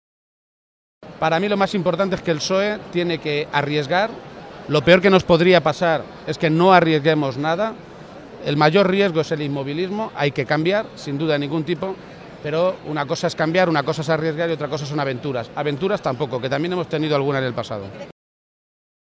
Emiliano García-Page ante los distintos medios de comunicación